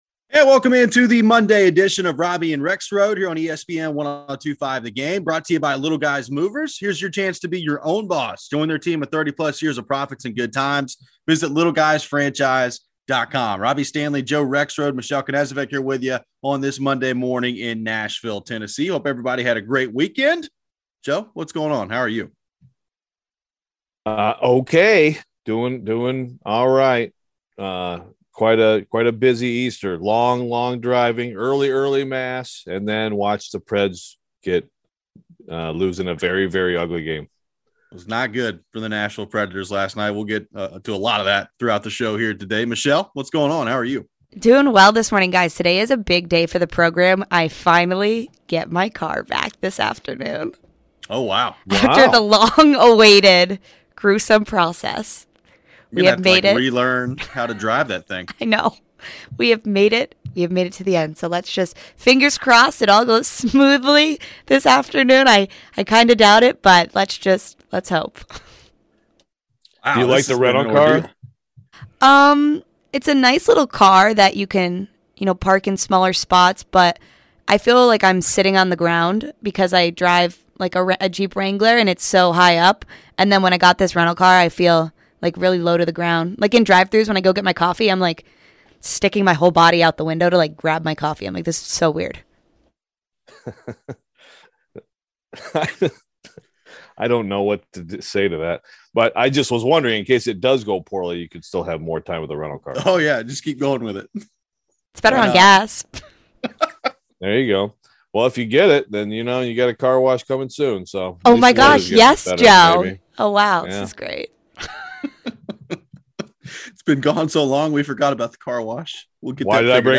The Nashville Predators lost to the St. Louis Blues 8-3. The phone and text lines were ringing constantly in hour one. Could the Nashville predators miss the playoffs? Should Poile and Hynes be fired if they don't make the playoffs?